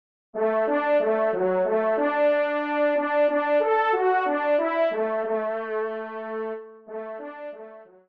FANFARE
Extrait de l’audio « Ton de Vènerie »
Pupitre de Chant